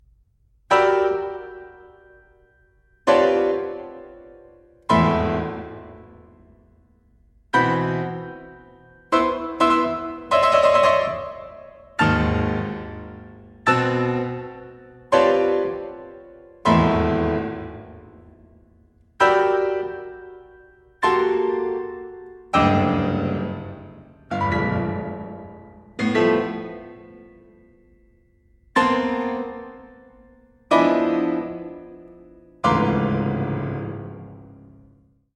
• Genres: Solo Piano